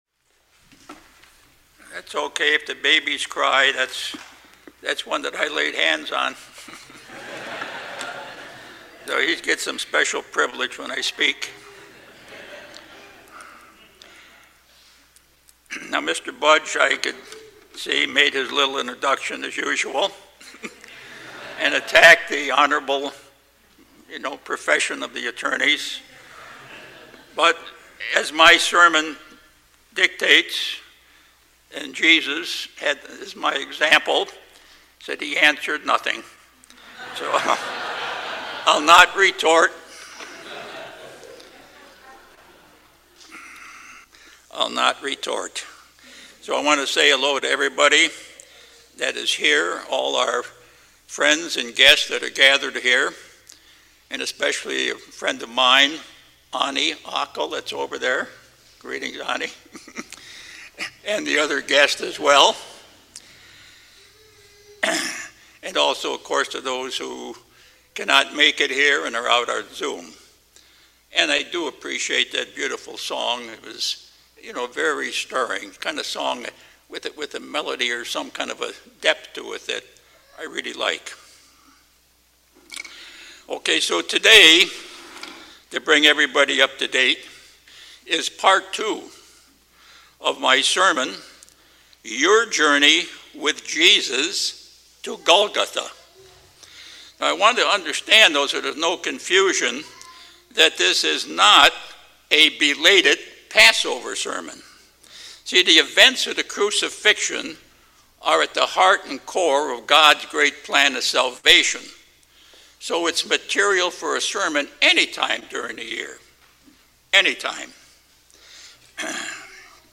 Sermons
Given in Bakersfield, CA